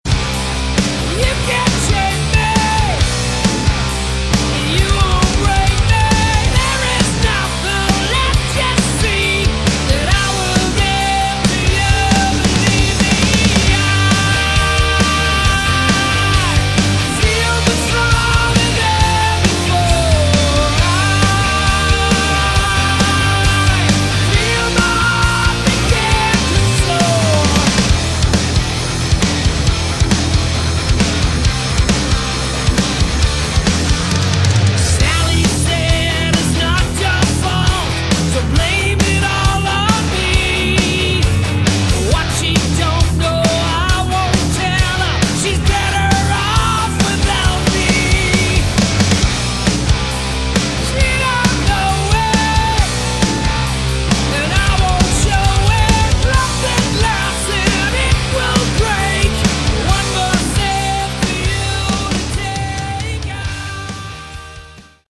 Category: Hard Rock
vocals
guitars
bass, keyboards
drums